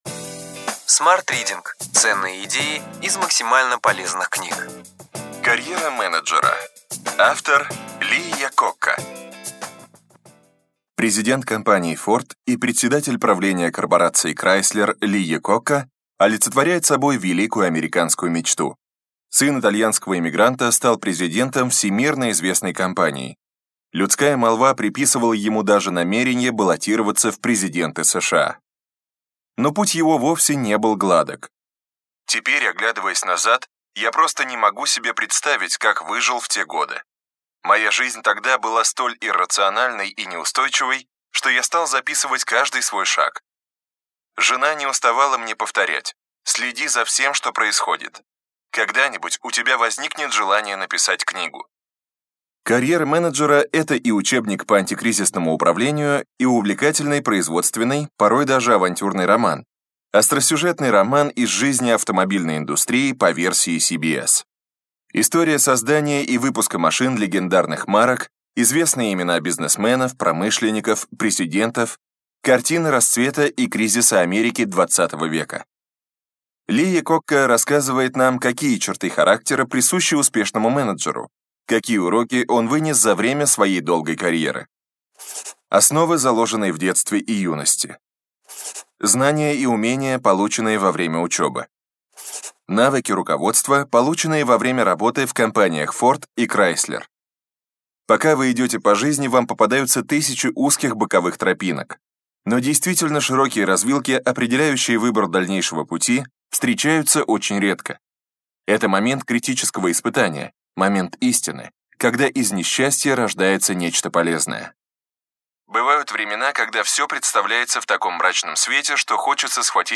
Аудиокнига Ключевые идеи книги: Карьера менеджера. Ли Якокка | Библиотека аудиокниг